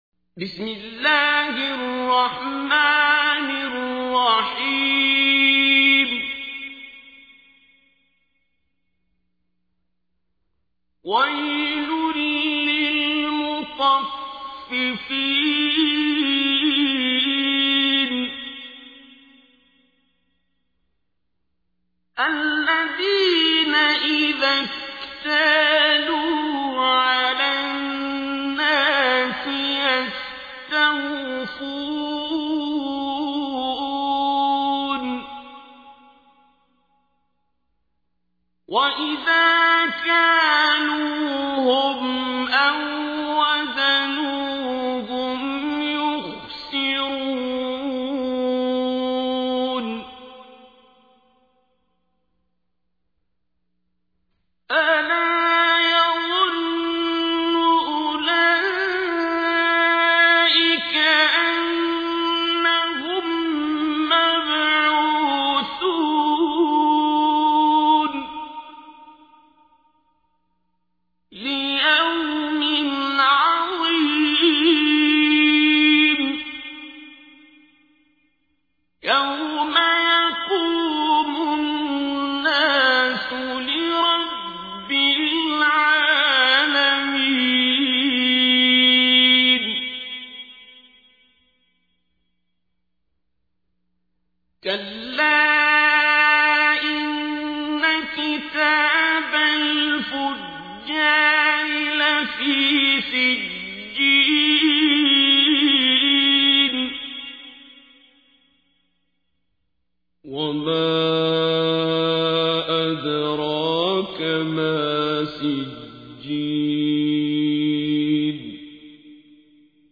تحميل : 83. سورة المطففين / القارئ عبد الباسط عبد الصمد / القرآن الكريم / موقع يا حسين